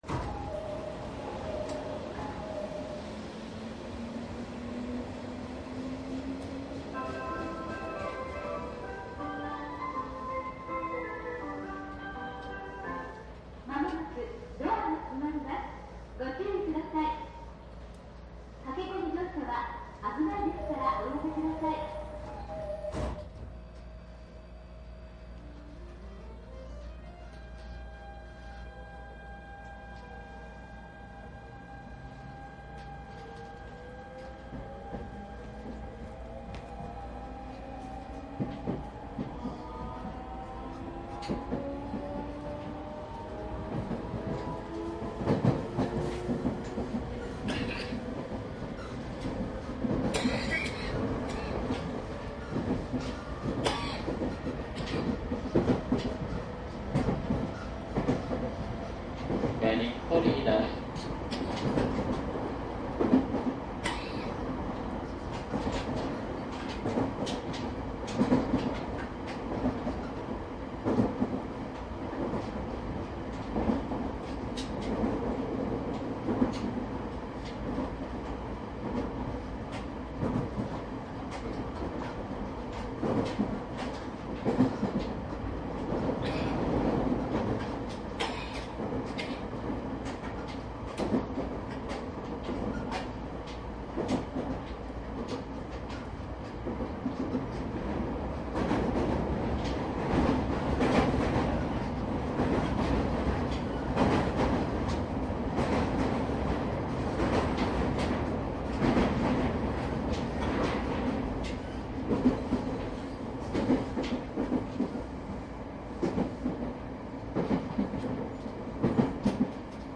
商品説明鉄道の車内走行音を収録したCDです。
①Ｅ５０１系（モハＥ500-9・シーメンスGTOVVVF）
常磐線 土浦→上野 01-12-09(日)収録
自動放送なし車掌氏による案内です。
注意事項収録機材は、ソニーDATと収録マイクソニーECM959を使用.。